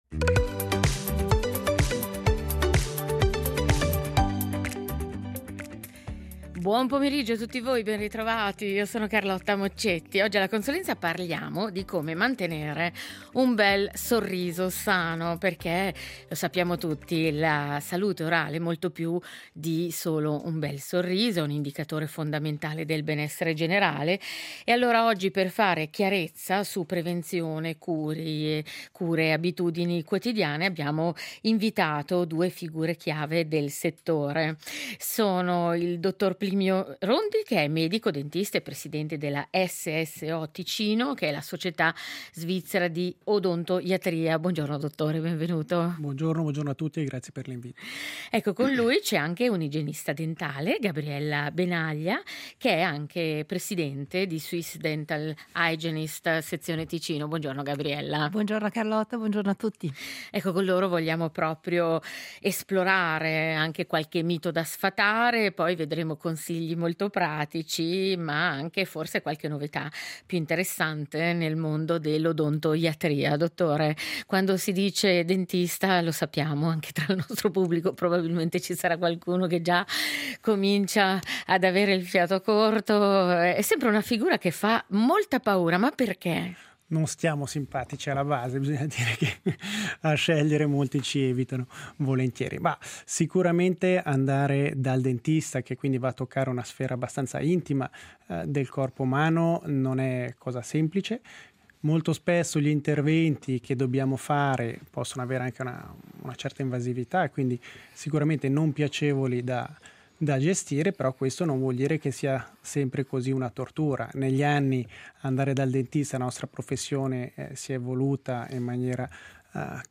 Per fare chiarezza su prevenzione, cure e abitudini quotidiane, abbiamo incontrato due figure chiave del settore: un dentista e un’igienista dentale. Con loro esploriamo miti da sfatare, consigli pratici e le novità più interessanti nel mondo dell’odontoiatria.